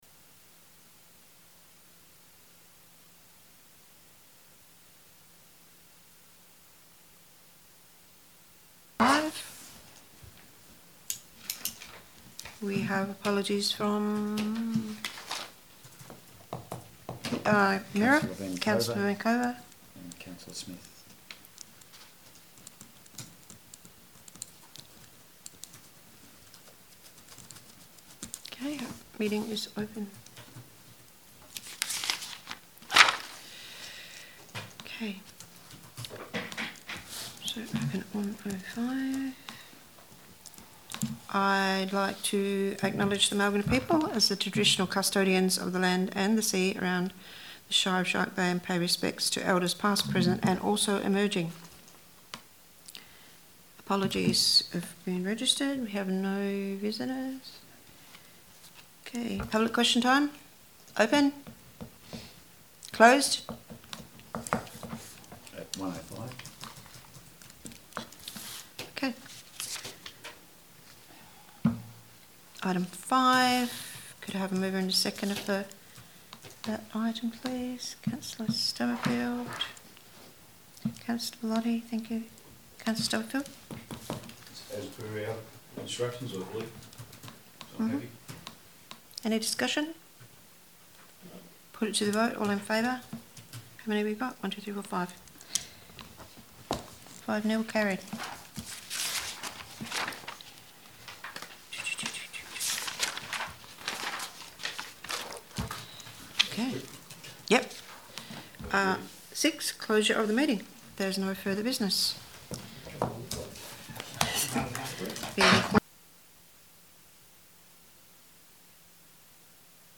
Special Council Meetings
Meetings are held in the Council Chambers, Shark Bay Recreation Centre, Francis Road Denham.